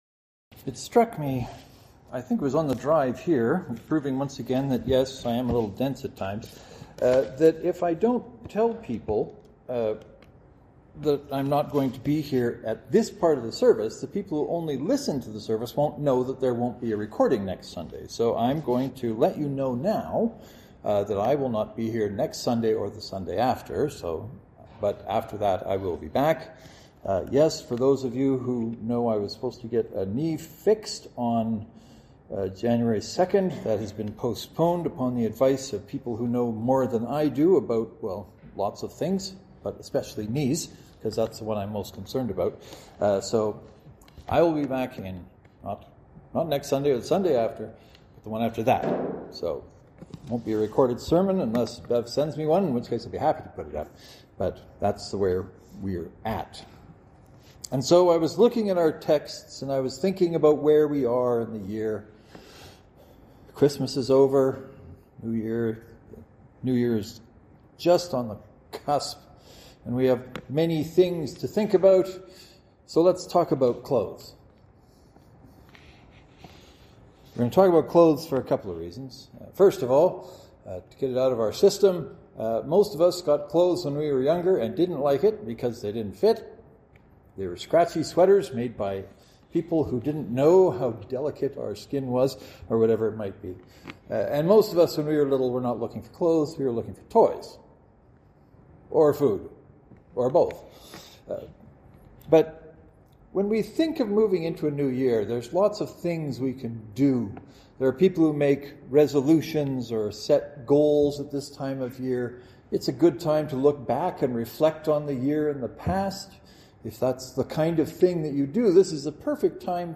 St. Mark’s Presbyterian (to download, right-click and select “Save Link As .